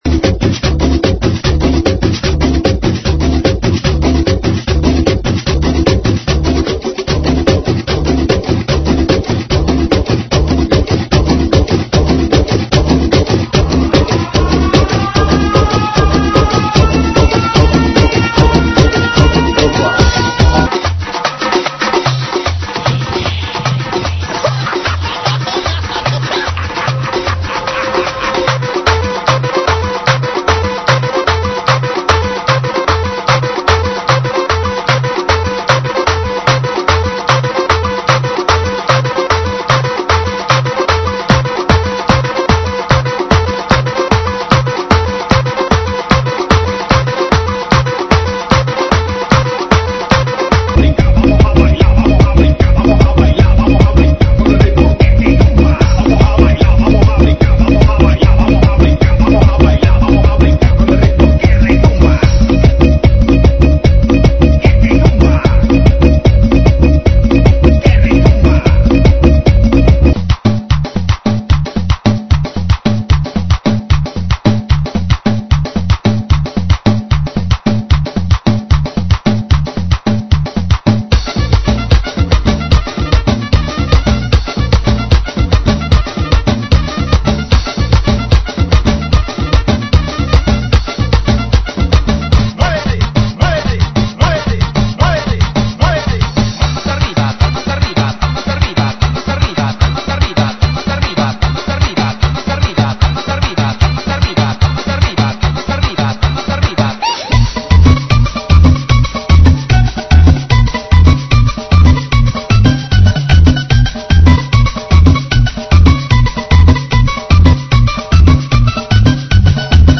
GENERO: LATINO – REMIX
AEROBICS (STEP-HILOW)